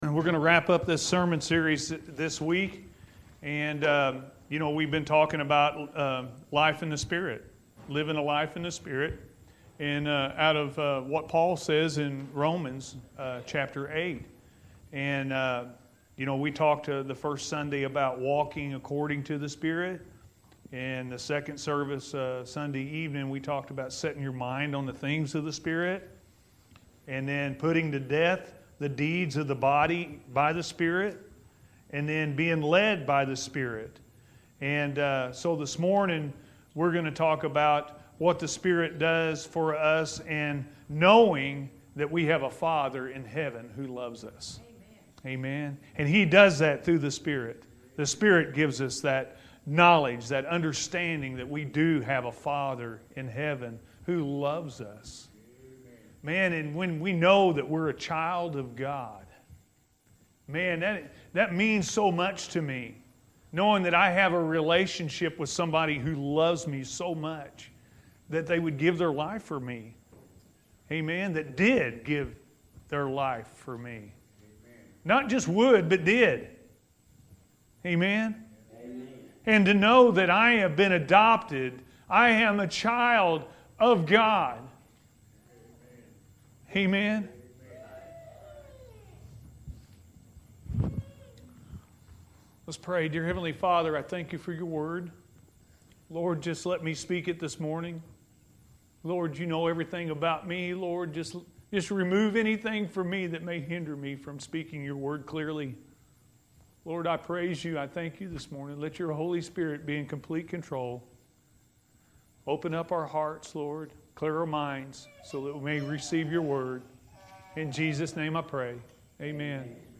We Are Heirs Of God-A.M. Service